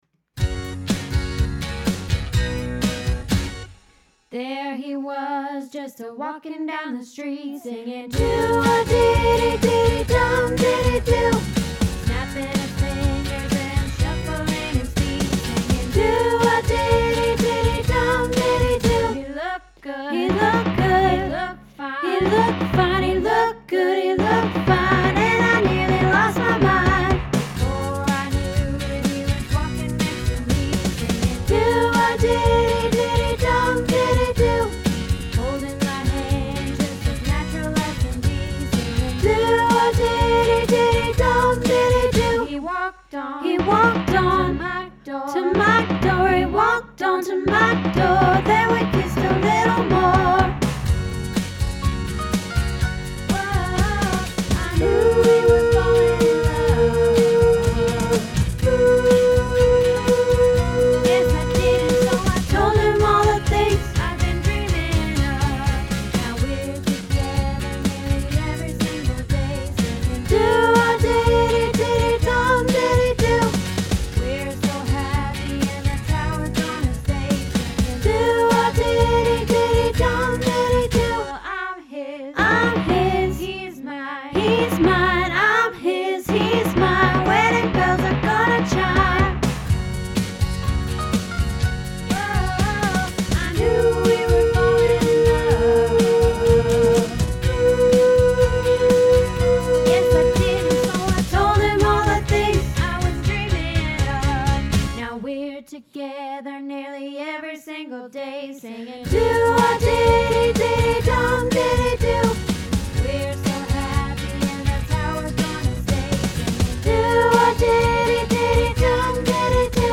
Doo Wah Diddy Soprano